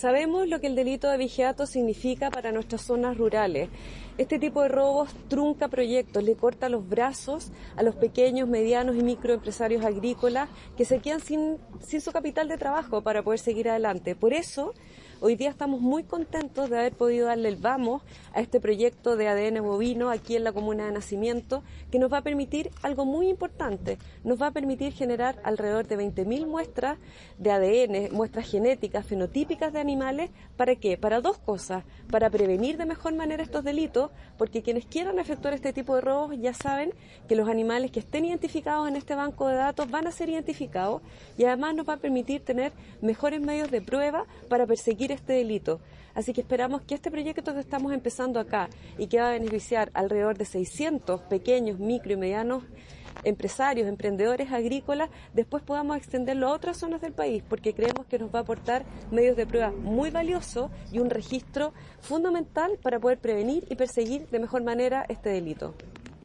Quien también estuvo en la actividad, fue la Subsecretaria de Prevención del Delito, María José Gómez, quien apuntó que “con esto se busca fortalecer el flujo de información y comunicación para entregar respuestas oportunas frente a estos delitos y aumentar los niveles de esclarecimiento judicial”.